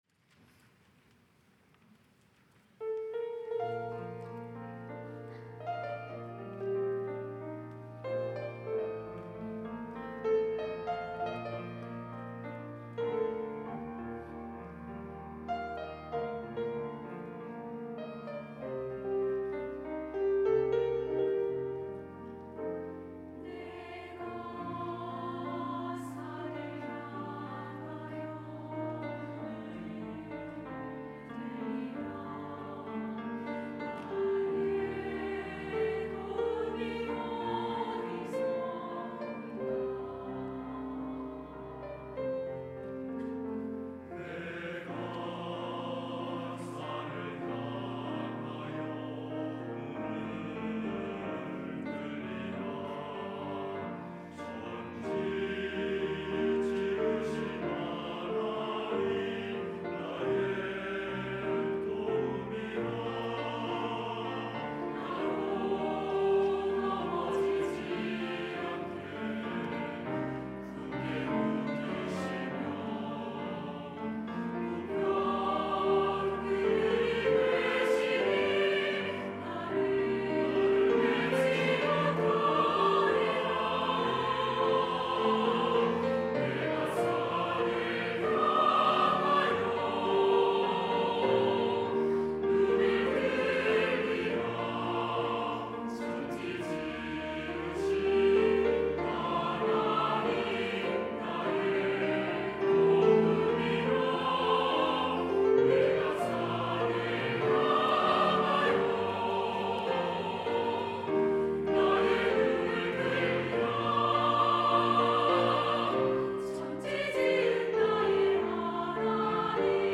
할렐루야(주일2부) - 내가 산을 향하여 눈을 들리라
찬양대